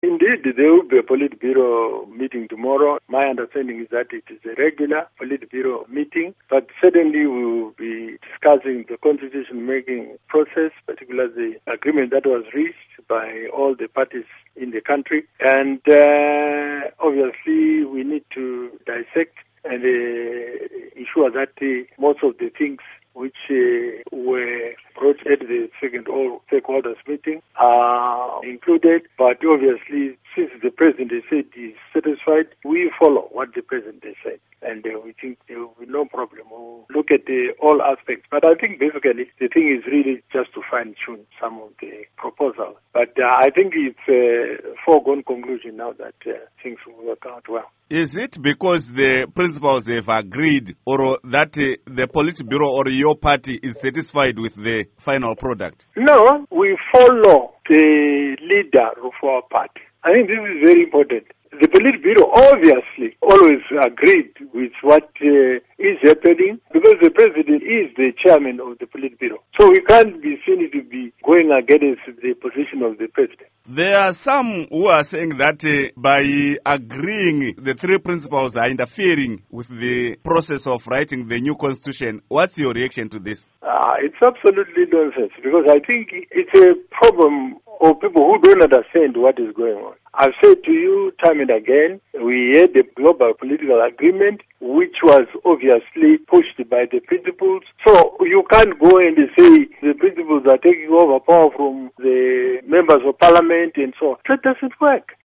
Interview With Rugare Gumbo